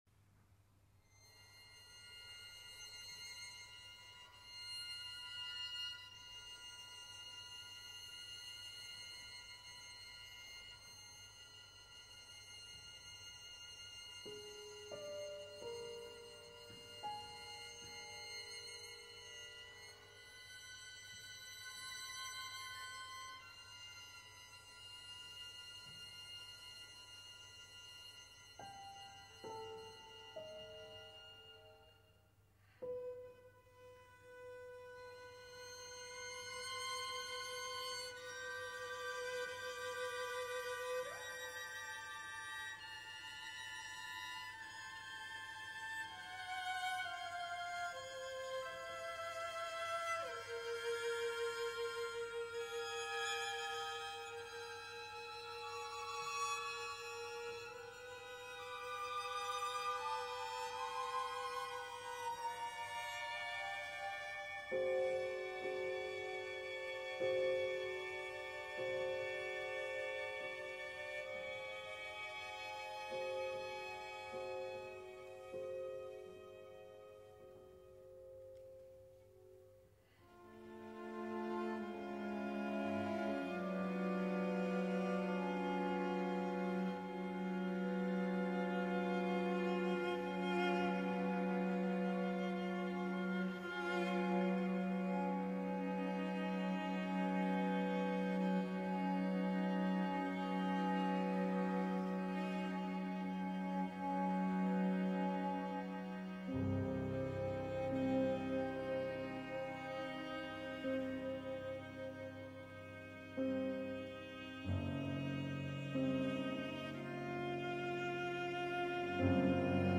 Klavierquintett [1998] | Dauer: 9’00“
Violinen
Viola
Violoncello
Klavier